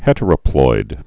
(hĕtər-ə-ploid)